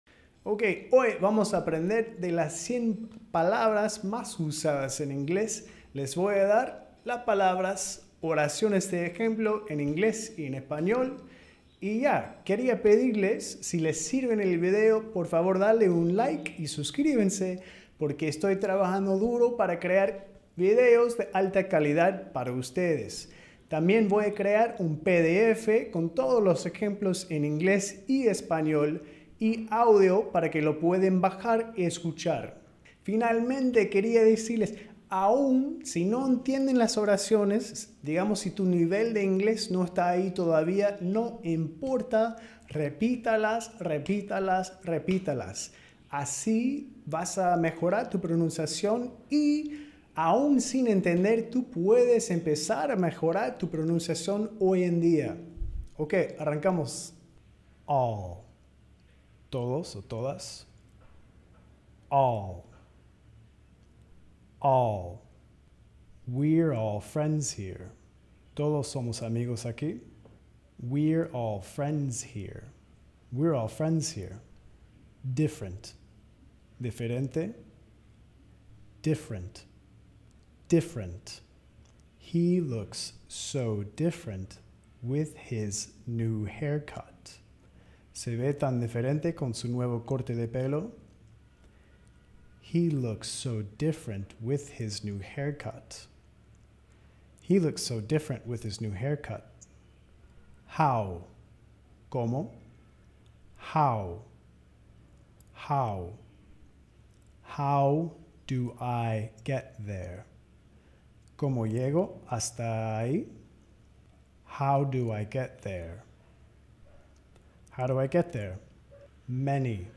Las 100 palabras más usadas en inglés con oraciones útiles (inglés americano)